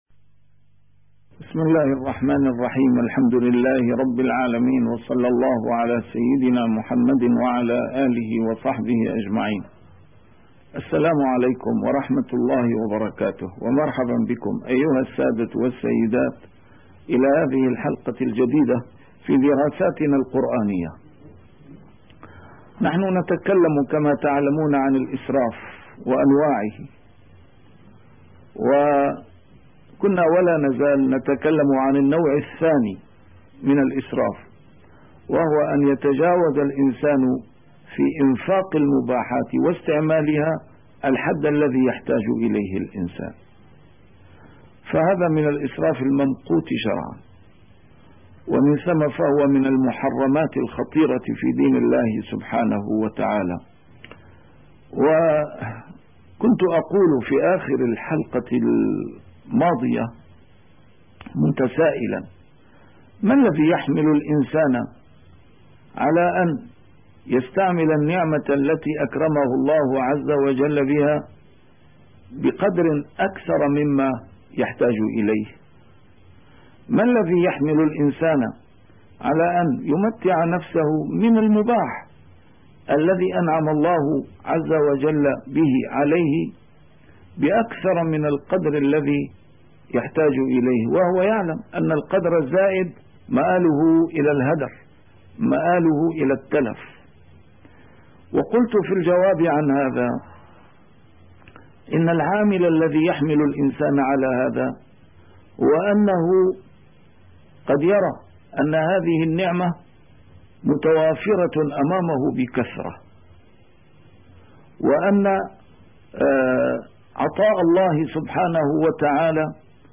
A MARTYR SCHOLAR: IMAM MUHAMMAD SAEED RAMADAN AL-BOUTI - الدروس العلمية - دراسات قرآنية - المال وسياسة الإنفاق في كتاب الله سبحانه وتعالى